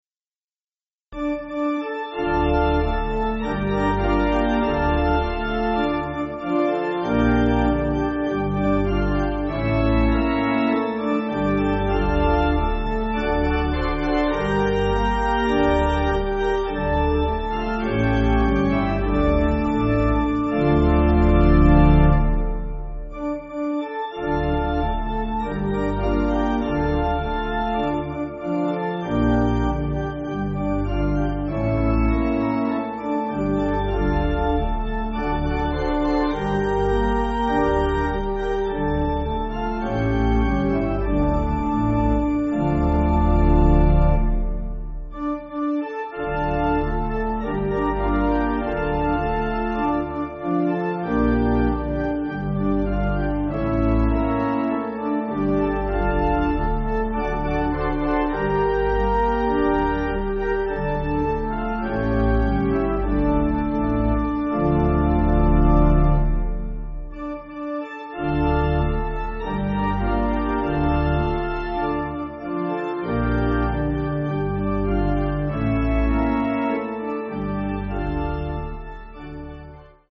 (CM)   7/Dm